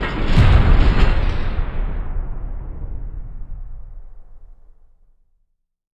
LC IMP SLAM 3C.WAV